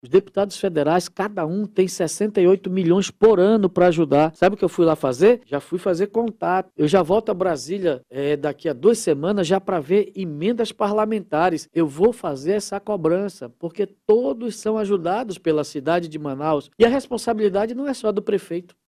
Em entrevista ao programa